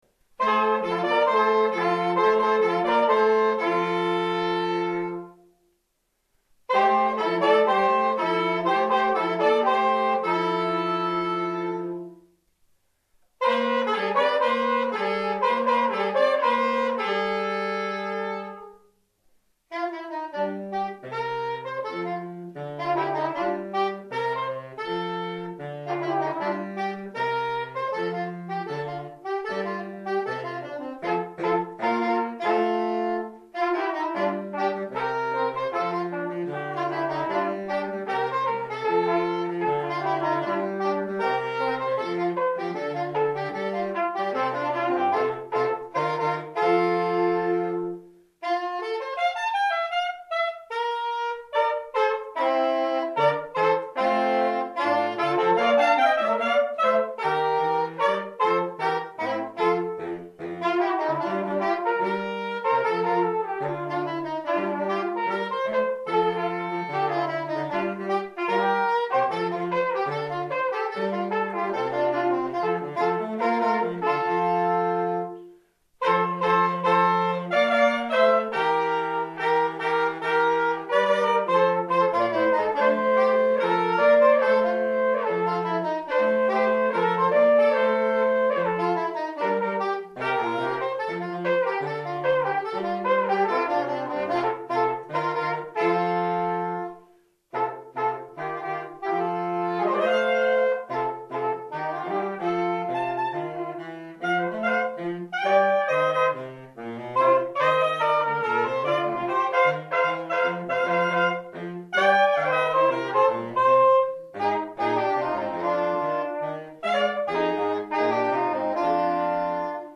ss, bars, tp/flh, !perf